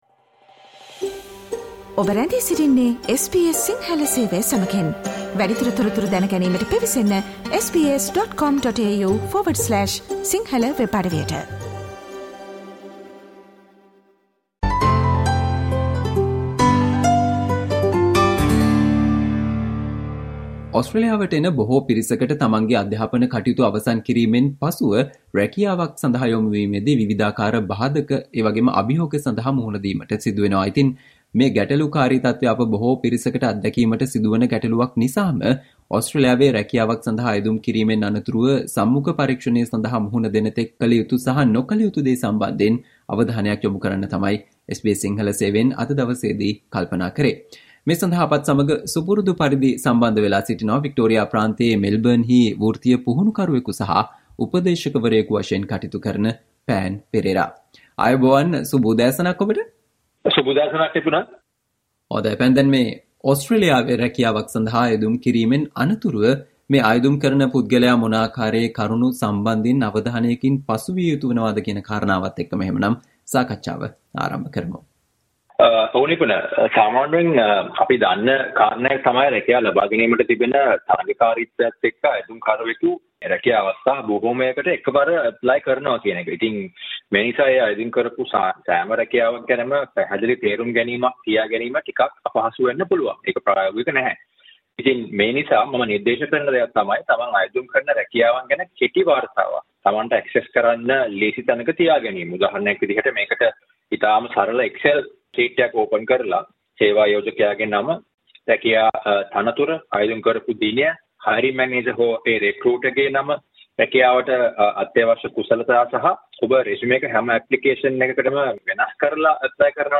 SBS Sinhala discussion on what you need to know about the main reasons you'll lose jobs no matter how many jobs you apply for in Australia